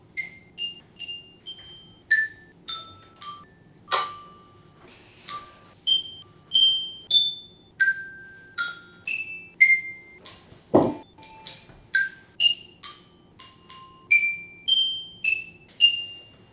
Tous les 6ème ont enregistré le même morceau d'un chant de Noël " We wish you a merry Chistmas ".